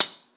surface_metal2.mp3